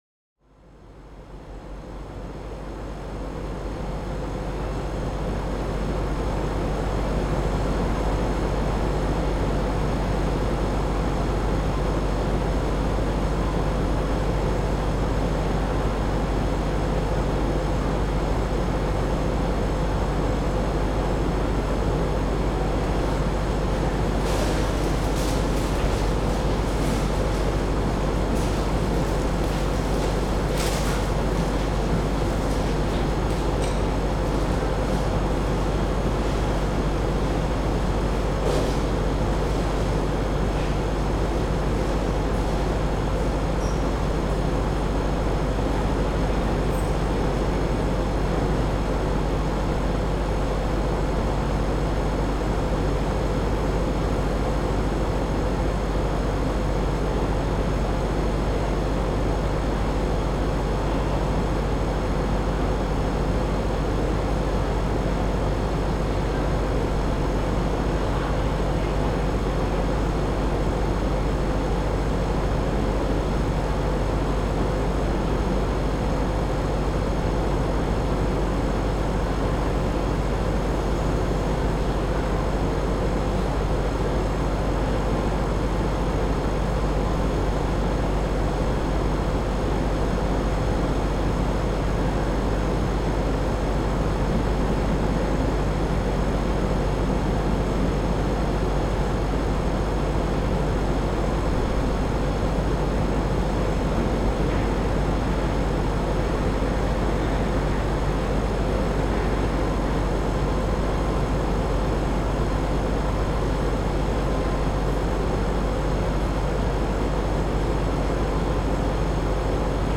AMB_Scene01_Ambience_R.ogg